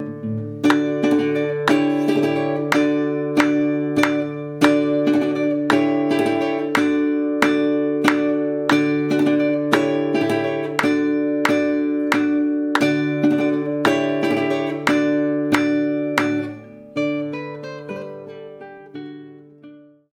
En este vídeo, escucha a partir del minuto dos para apreciar el cambio de acentuación característico de la hemiolia, aunque lo puedes distinguir desde el principio de la pieza:
Por si no has podido apreciarlo, hemos aislado e interpretado el rasgueo con acompañamiento de palmas:
Rasgueo Canarios con palmas.
CanariosPalmas.m4a